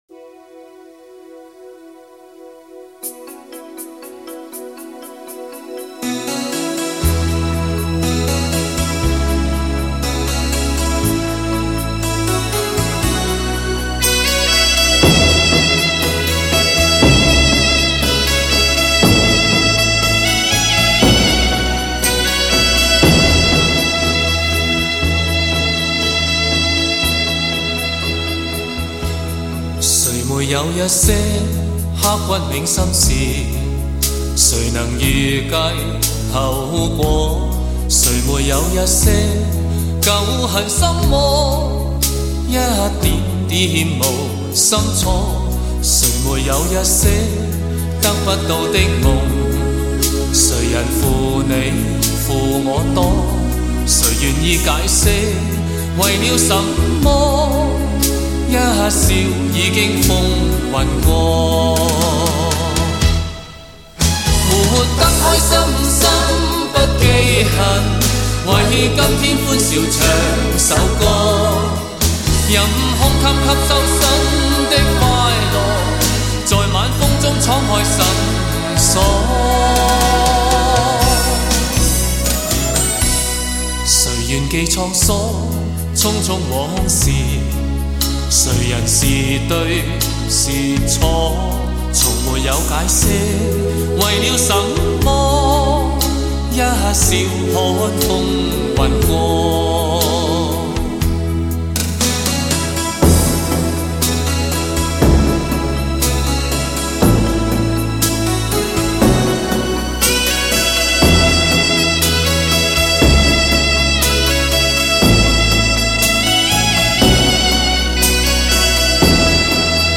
雄伟典范乐曲 必唯天作之合Hi-Fi典范 极致人声
高密度24BIT数码录音
开头的萨克斯等音乐真的很让人陶醉，整首歌听下去必定让人心旷神怡。